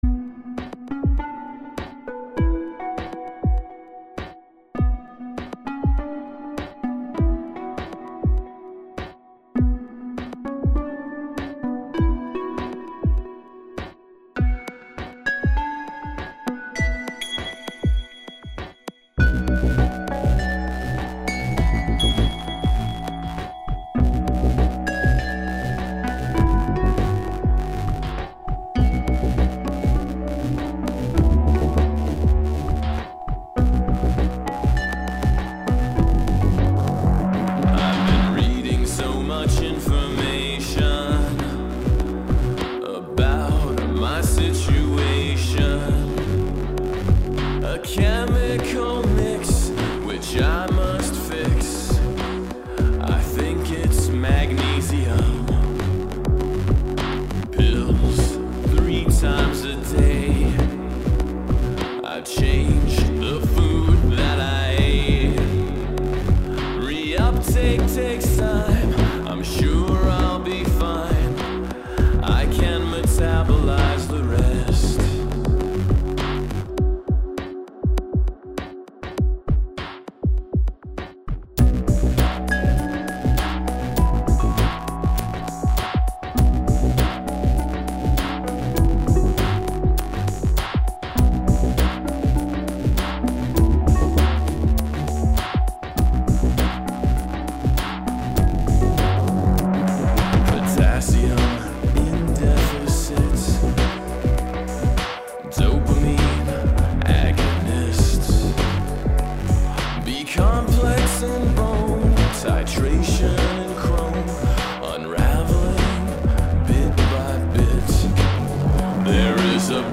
I think it still needs some backing vocals on the chorus.